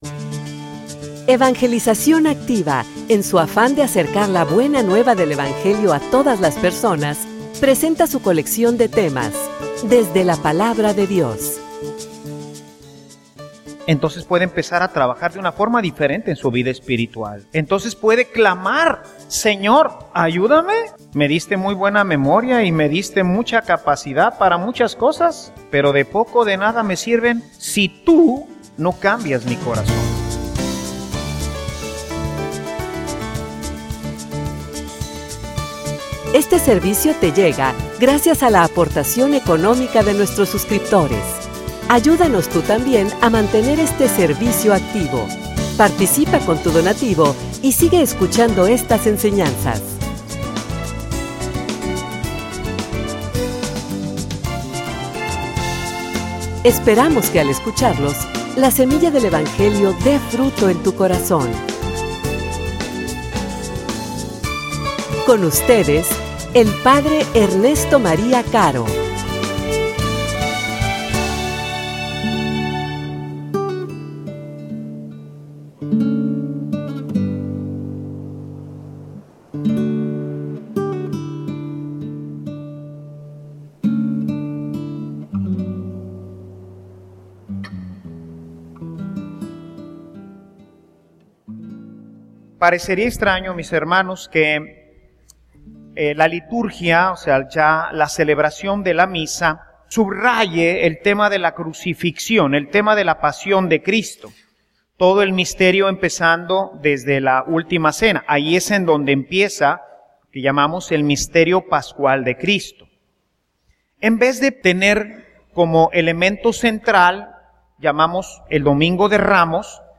homilia_Una_vida_congruente.mp3